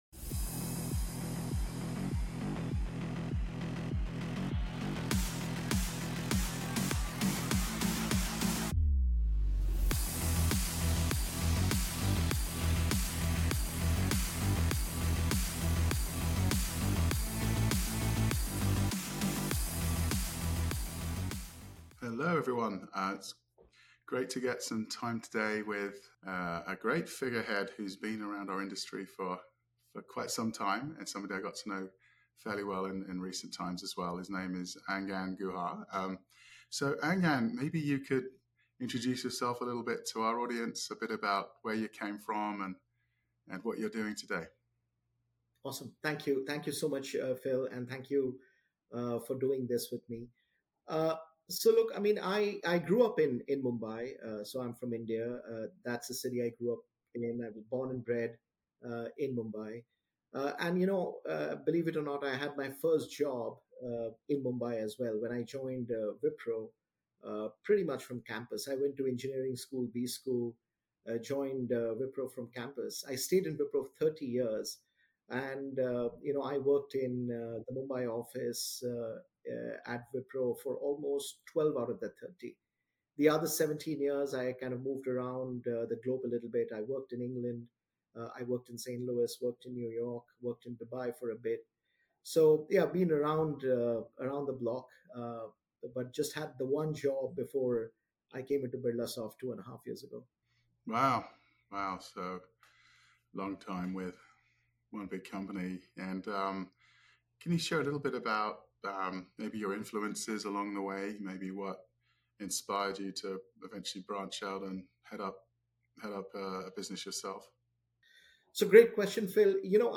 Fireside Chat | Next-Gen IT: Talent, Trust, and Tech in a Changing Outsourcing World